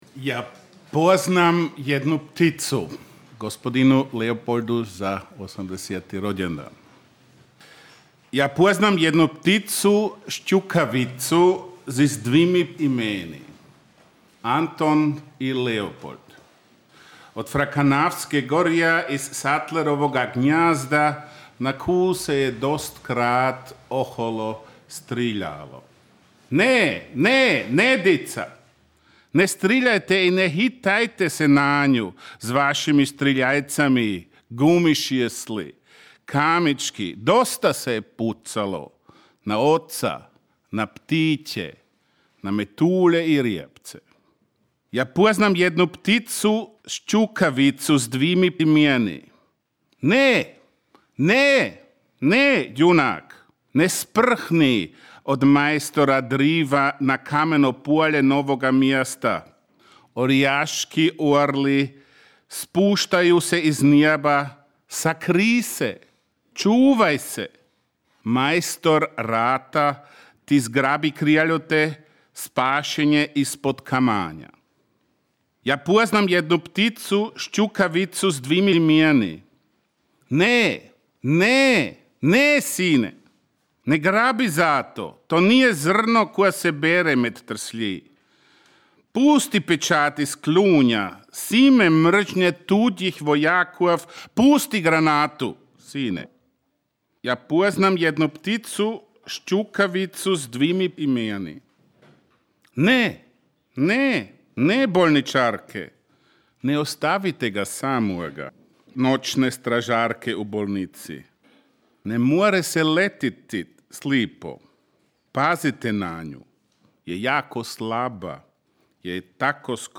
čita: